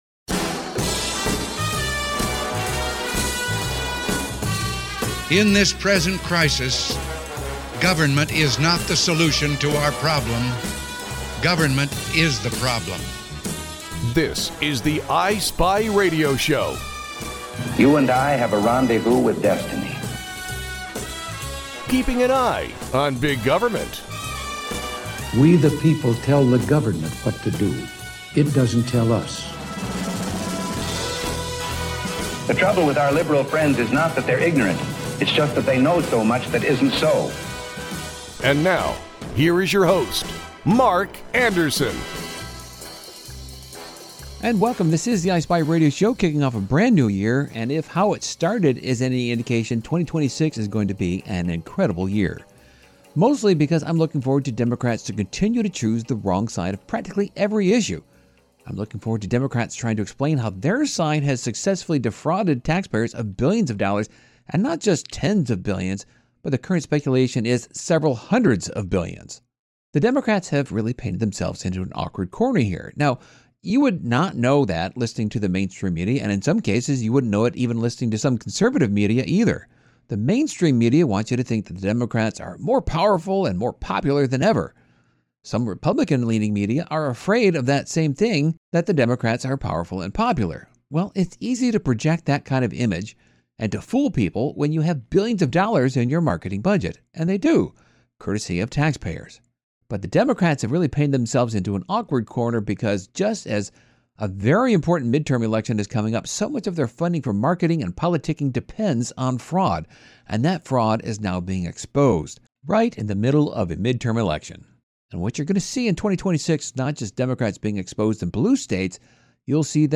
conservative talk radio